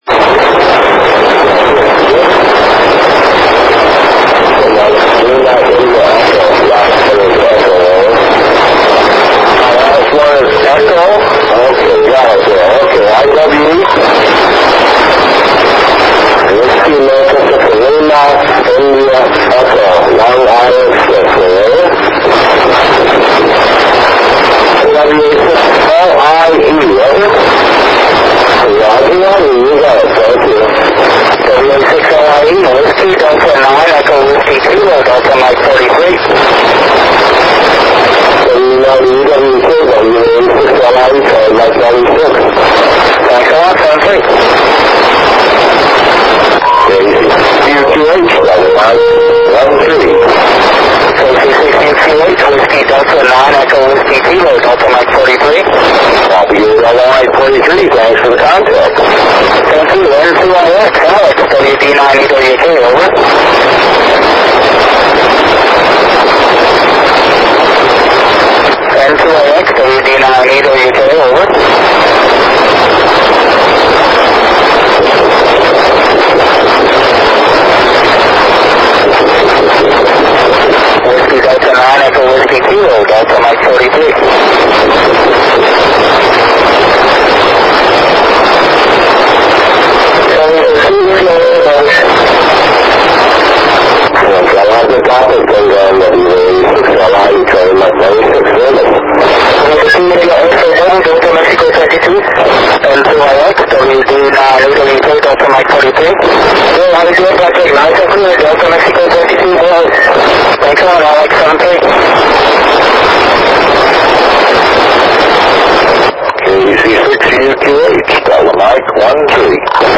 ISS cross-band repeater, 29 December 2005 @ 0058 UTC
I used an Icom IC-W32A HT at 5W with an Arrow Antennas handheld Yagi to record this pass (and make some QSOs during the pass).